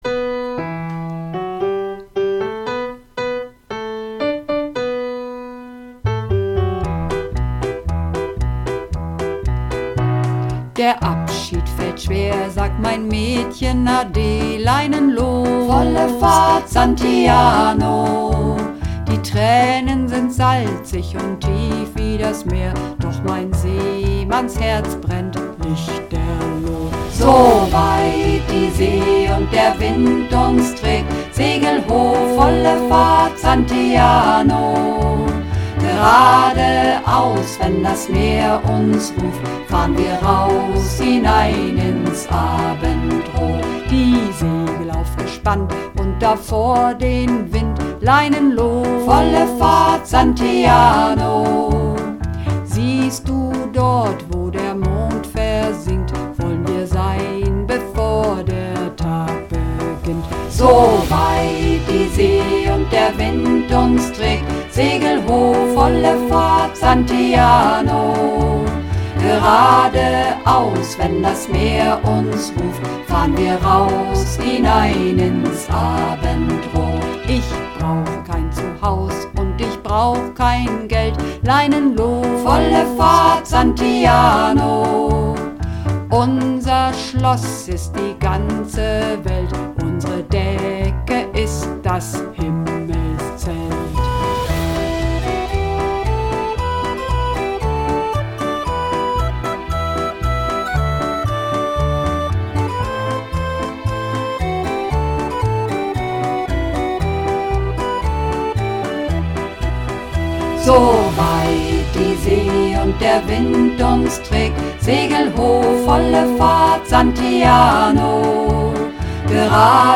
Übungsaufnahmen - Santiano
Runterladen (Mit rechter Maustaste anklicken, Menübefehl auswählen)   Santiano (Mehrstimmig)